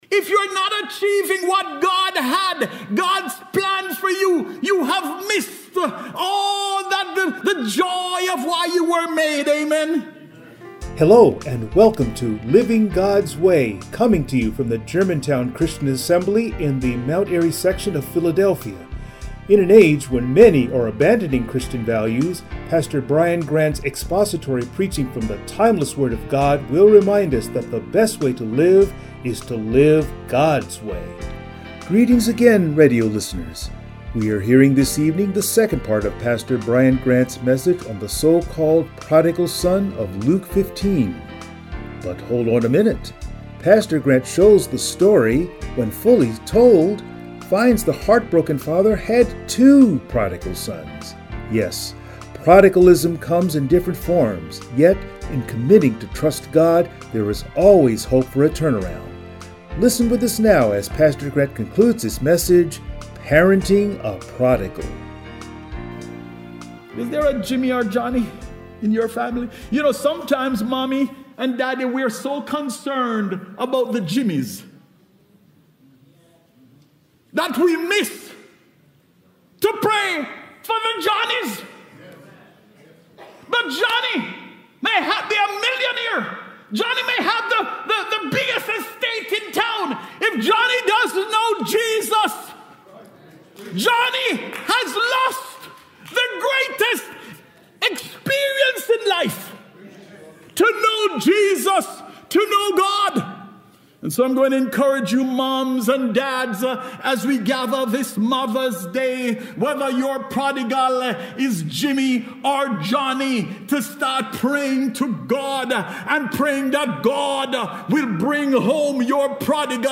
Passage: Luke 15:11-32 Service Type: Sunday Morning